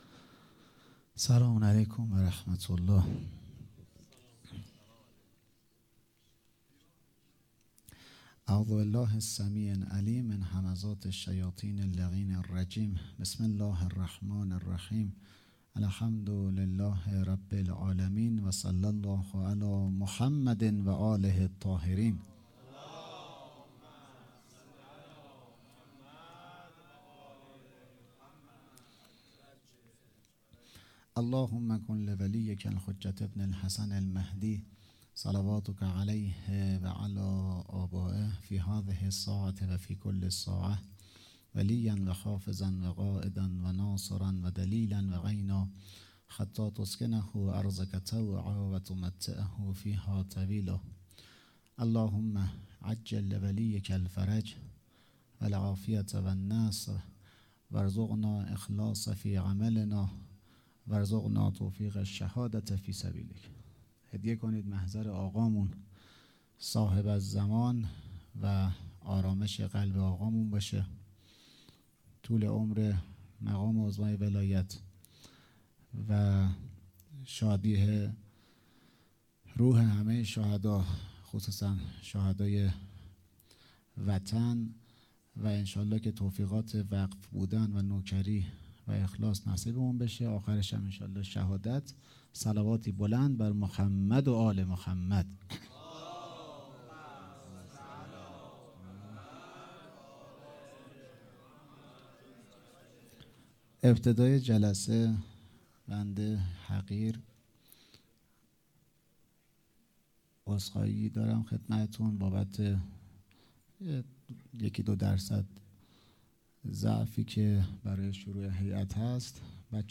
سخنرانی شب اول محرم
مراسم شب اول محرم